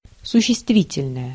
Ääntäminen
UK : IPA : /naʊn/ US : IPA : /naʊn/